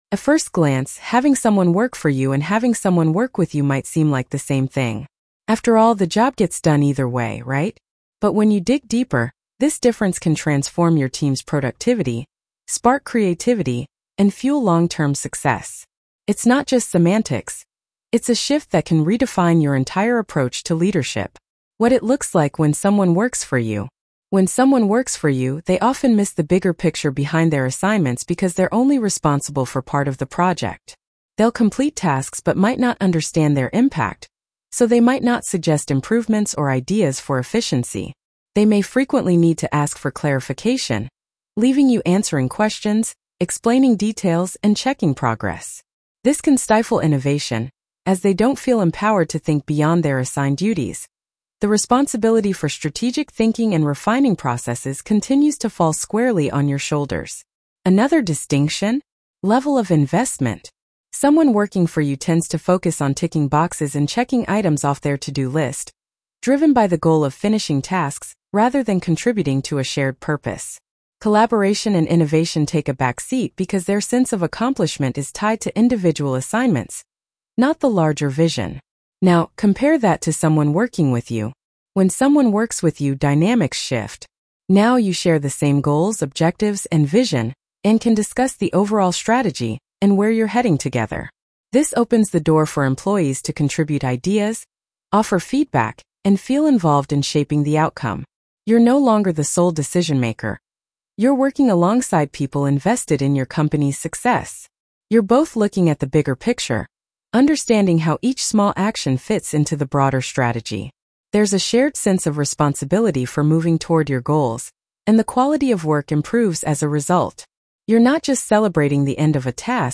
Working With vs. Working For Blog Narration.mp3